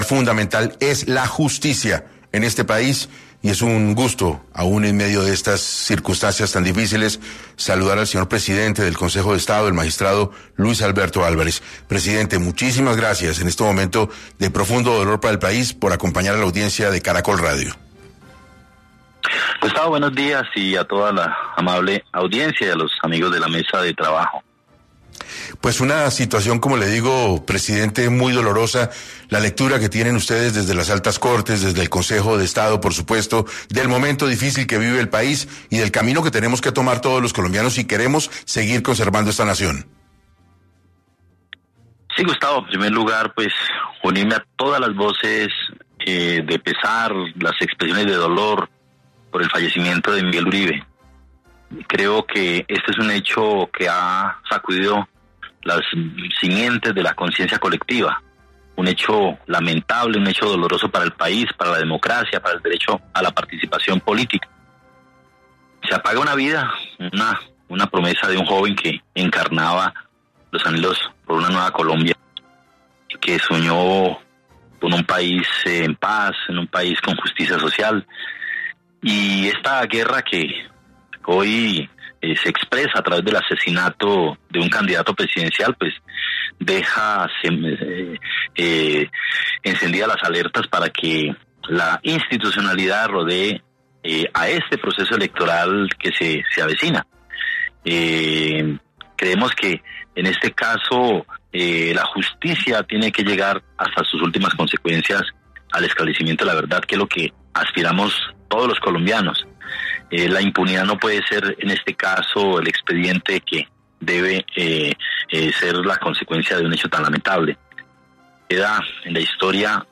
El Magistrado Luis Alberto Álvarez lamentó en 6AM el fallecimiento del precandidato presidencial.